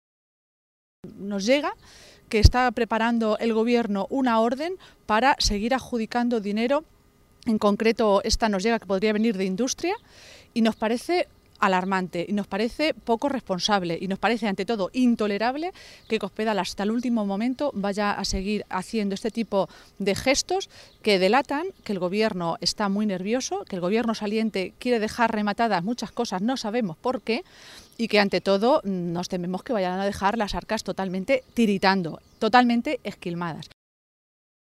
Se pronunciaba Maestre de esta manera esta mañana, en una comparecencia ante los medios de comunicación, en Toledo, durante la reunión de Page con los otros 14 diputados regionales socialistas electos en las elecciones del pasado 24 de Mayo.